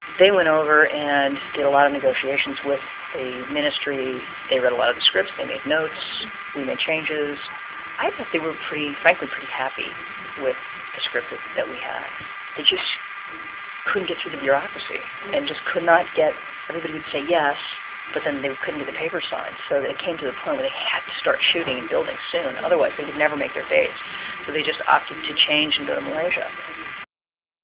Interview Highlights (Audio)